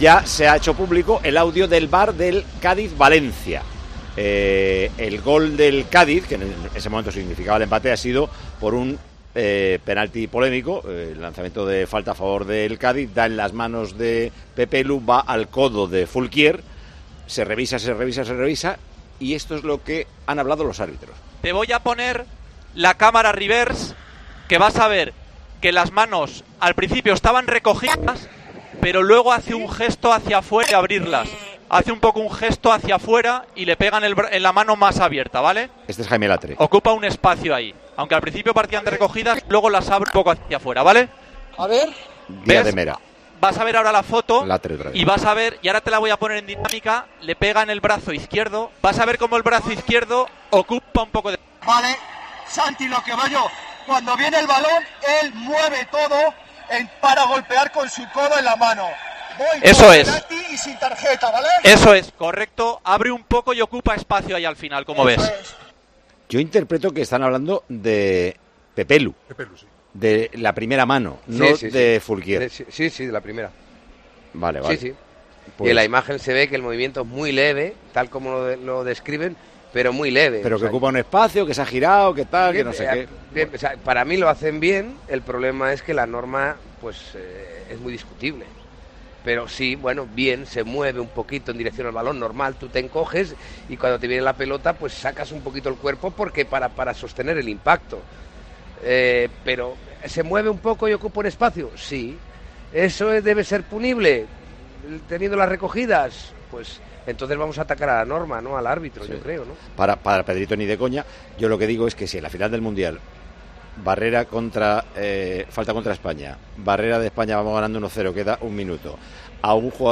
En Tiempo de Juego pudimos escucharlo y volvimos a analizar la acción. Pincha en el siguiente audio para conocer la opinión del equipo de comentaristas.